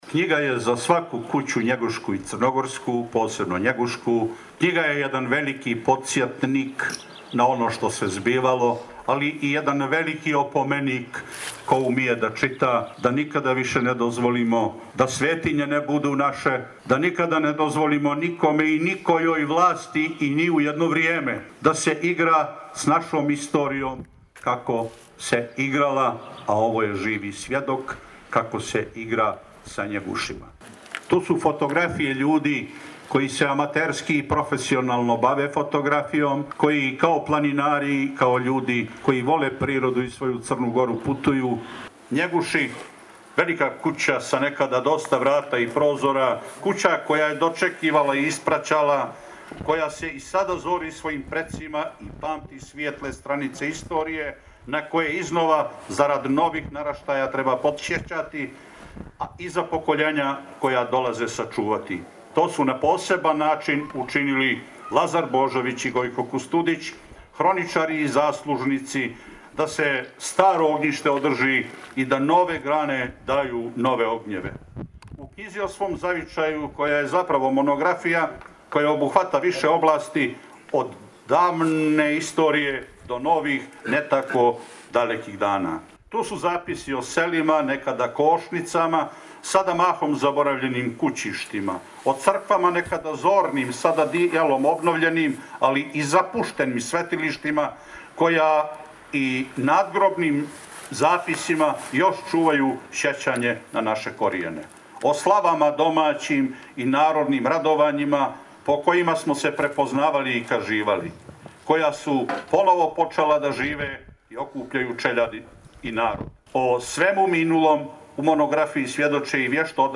Sa promocije
U  prazničnom danu, crnogorskoj nezavisnosti posvećenom  na  gumnu ispred  ljetnikovca kralja Nikole,  živa i pisana riječ koja na ovim prostorimna od vjekova nalazi iskru u kamenu , uz tradicionalne diple, čašicu rakije i njeguiški sir i pršut te  prijateljski stisak ruku, zvanično je počelo ovogodišnje Njeguško kulturno ljeto.
Ova ideja popraćena je dugim  aplauzom prisutnih koji su na ariji podlovćenskoj disali punim plućima i punim srcem rodoljublja.